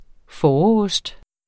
Udtale [ ˈfɒːɒ- ]